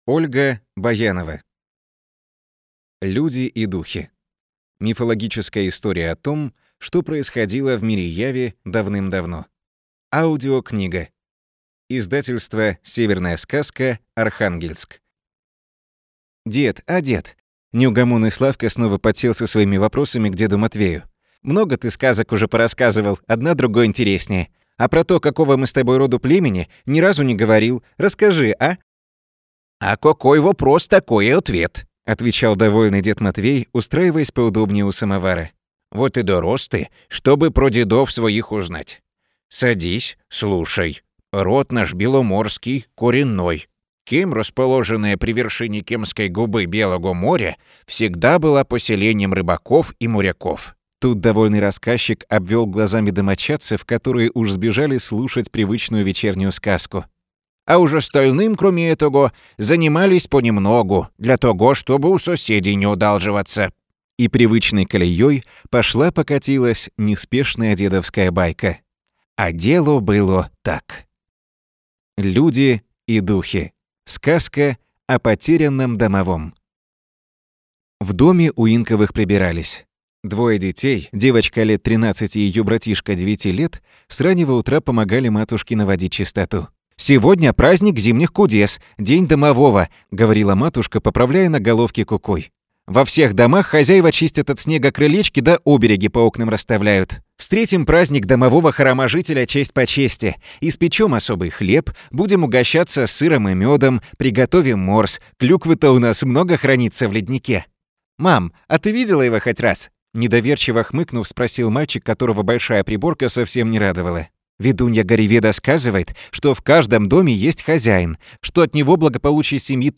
Аудиокнига «Люди и Духи» | Славяне
Аудиокнига, которая понравится взрослым, а детям ещё больше!
Слышишь лишь голос чтеца, а, кажется, будто своими глазами видишь всё, что происходит с героями сказки. Каждому из них рассказчик даёт свой голос, передаёт их волнения и тревогу, живой интерес и радость.